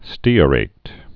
(stēə-rāt, stîrāt)